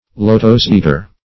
lotos-eater.mp3